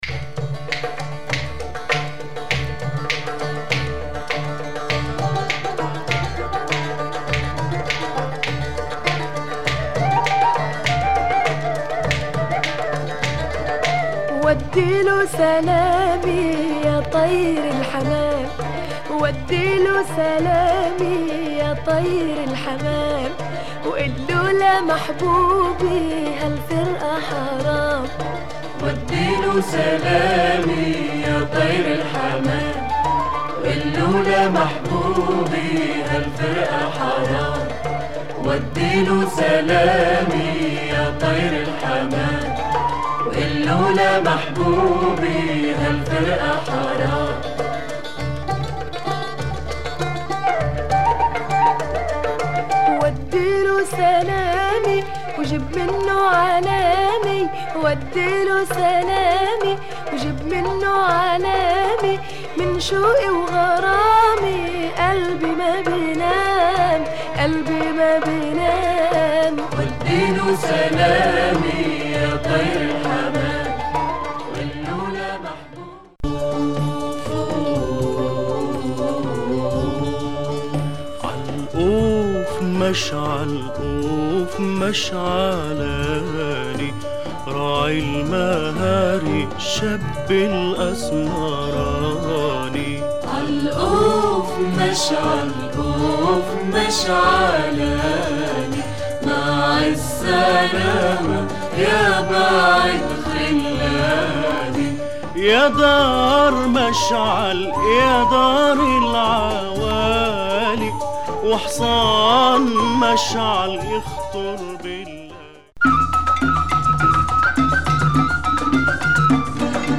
Lebanese album